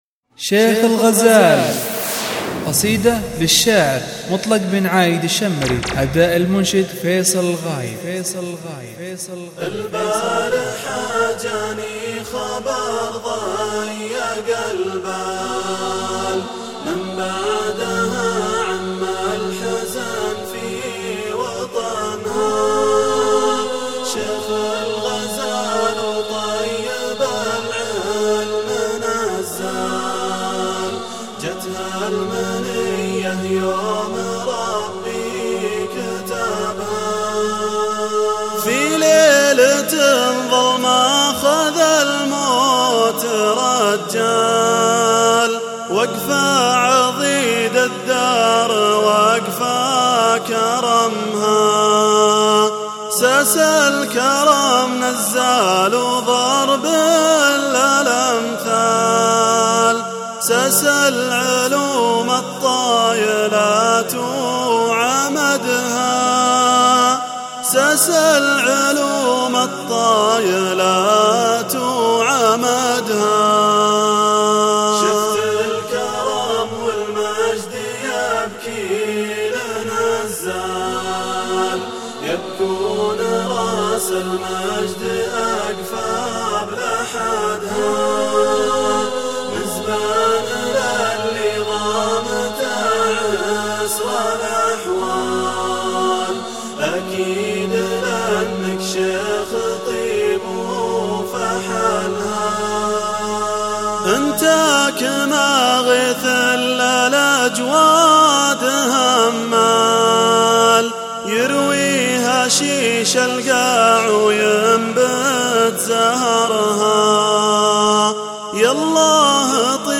وهي جديدي من الاناشيد الخاصة ويهمني رأيكم الهادف ونقدكم البناء أحبتي
هندسة الصوت : استديو نوايف